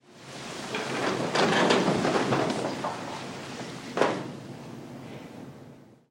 На этой странице собраны разнообразные звуки, связанные с судебными процессами: от характерных ударов молотка судьи до шума зала заседаний.
Толпа вернулась на свои места в зале суда